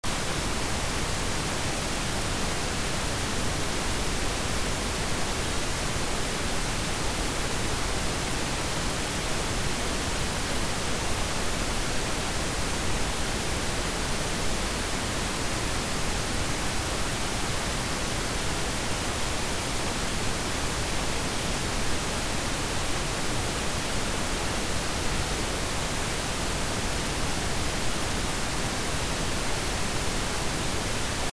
Ослабленное везикулярное дыхание левое легкое